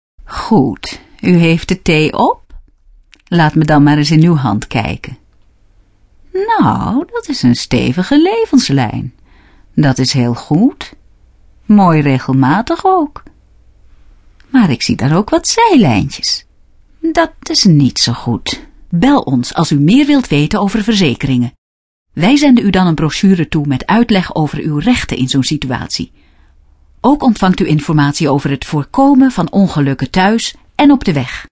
Hieronder wat stemvoorbeelden